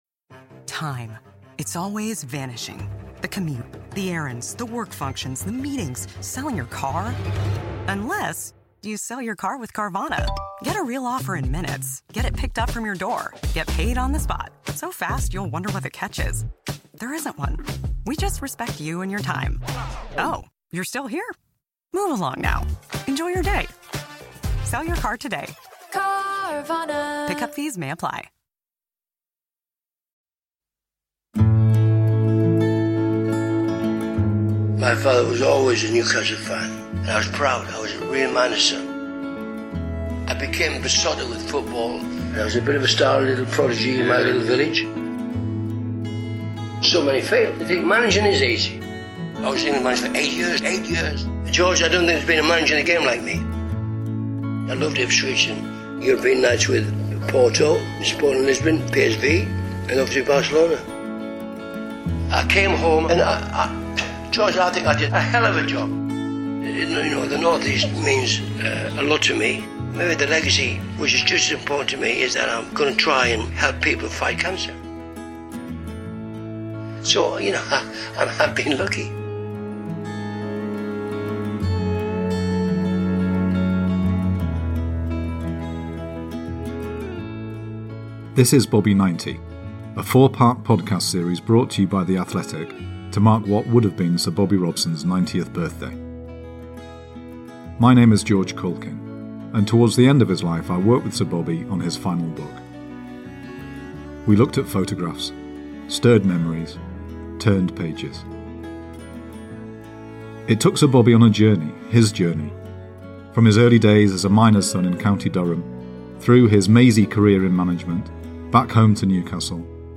Thanks to the generosity of Sir Bobby's family we are able to publish the recording of those conversations, featuring memories of his life as a miner's son, watching some of the greatest ever Newcastle United teams, and embarking on an incredible football career of his own.